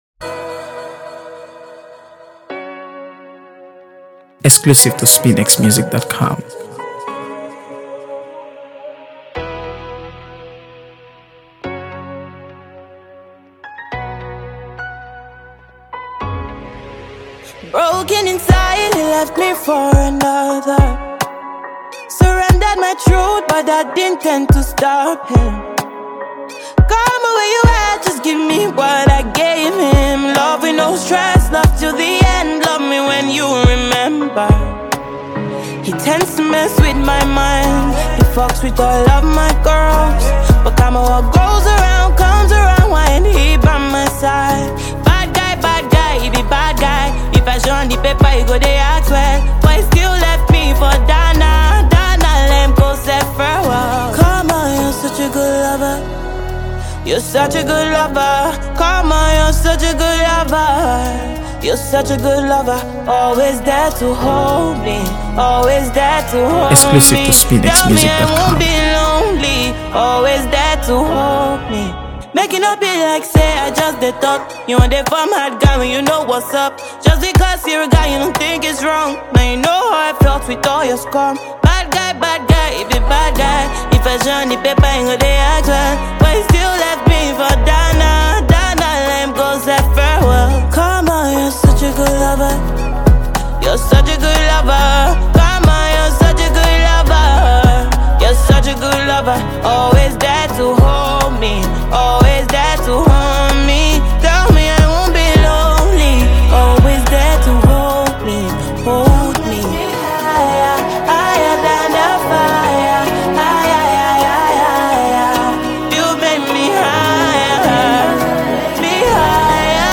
AfroBeats | AfroBeats songs
Nigerian singer-songwriter